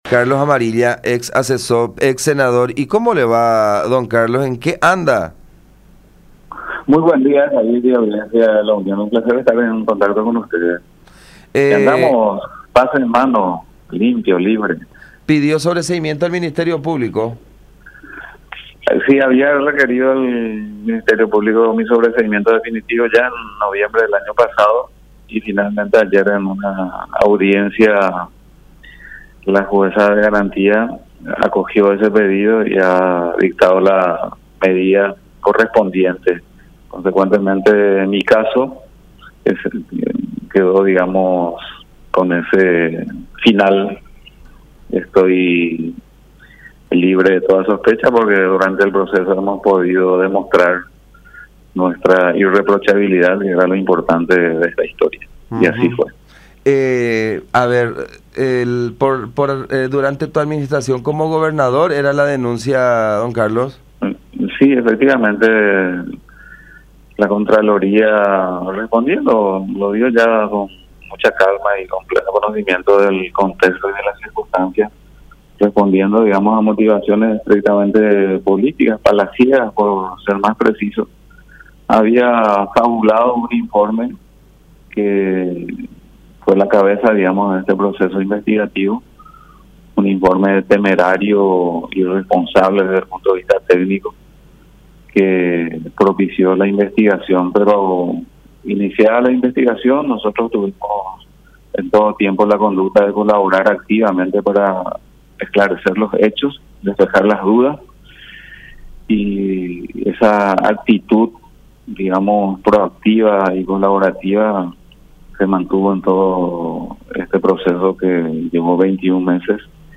“La jueza penal de Garantías resolvió mi sobreseimiento. Una vez más quedó probada nuestra honestidad”, expresó Amarilla en comunicación con La Unión con relación a la investigación que le fue abierta por un presunto faltante de G. 9.000 millones cuando se desempeñaba como gobernador del Departamento Central.